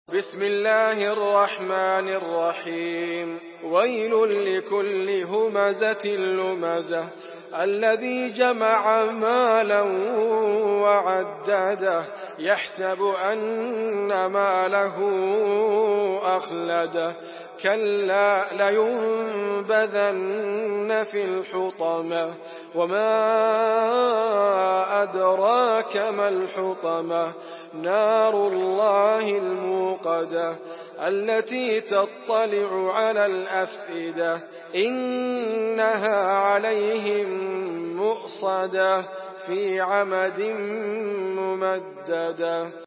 سورة الهمزة MP3 بصوت إدريس أبكر برواية حفص
مرتل